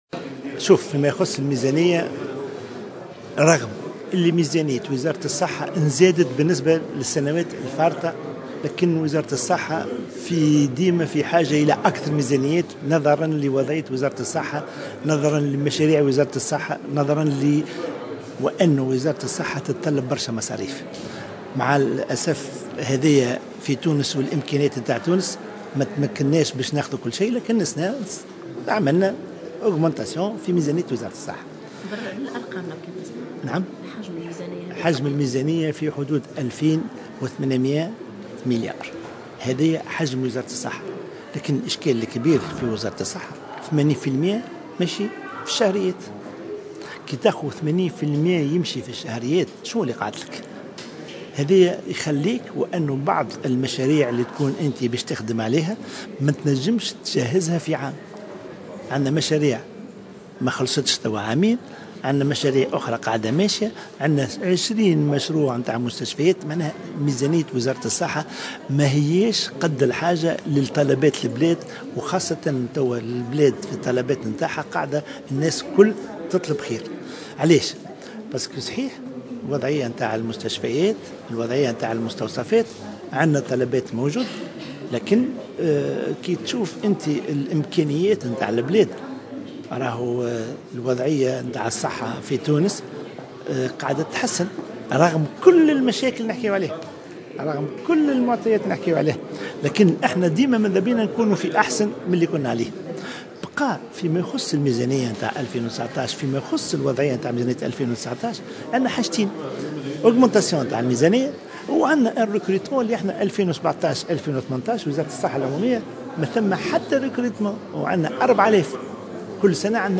وأفاد الشريف خلال جلسة استماع خصصتها لجنة الصحة والشؤون الاجتماعية بمجلس نواب الشعب للنظر في ميزانية وزارة الصحة لسنة 2019، أن الانتدابات المقترحة غير كافية لتلبية الحاجيات المطروحة لتدعيم وتشغيل الاحداثات المقدرة بحوالي 4000 خطة، لاسيما وانه لم يقع فتح باب الانتداب بوزارة الصحة خلال سنتي 2017 و2018 ولم يقع تعويض المغادرين، مؤكدا انه طلب من رئيس الحكومة يوسف الشاهد الترفيع في عدد الانتدابات إلى 2000 خطة.